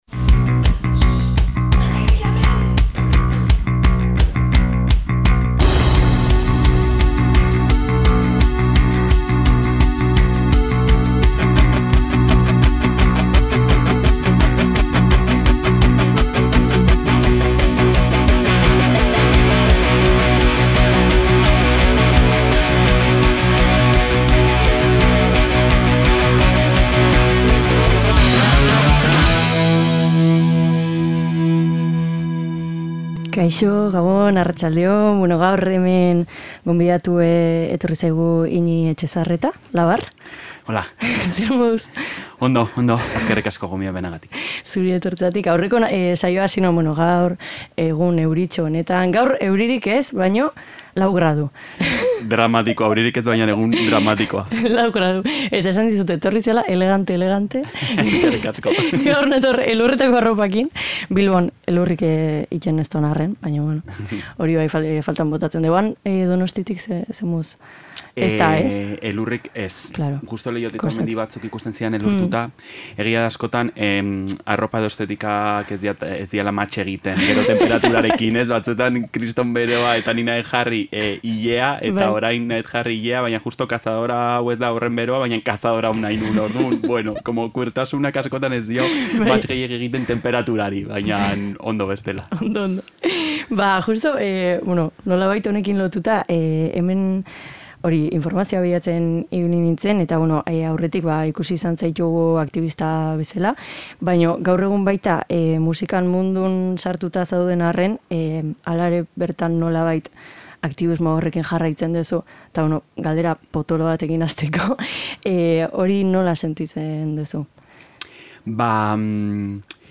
Egun hauetako tenperatura berotzeko elkarrizketa ederra datorkigu oraingoan.